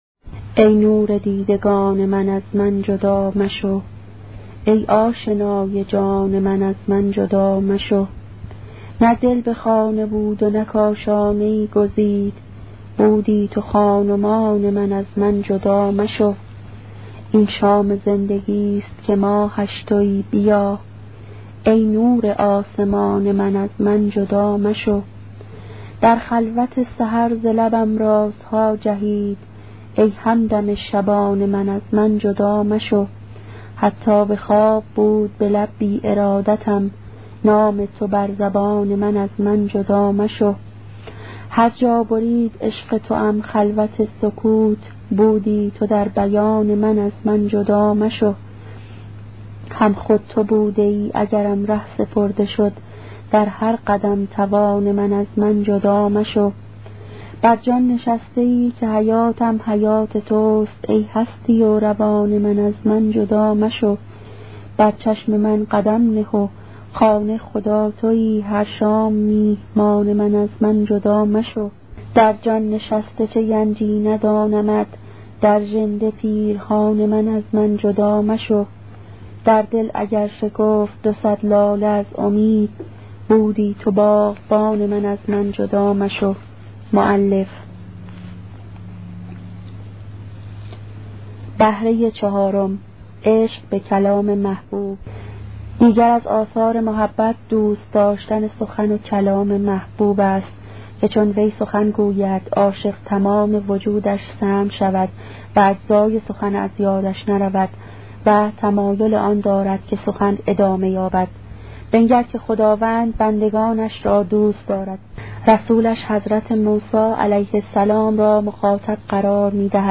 کتاب صوتی عبادت عاشقانه , قسمت دهم